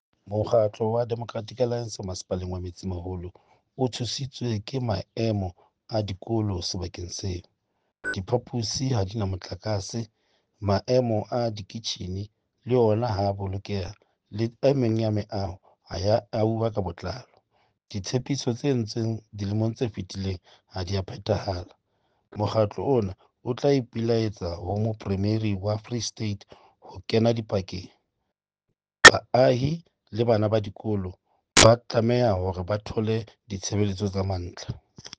Sesotho soundbites by Cllr Stone Makhema.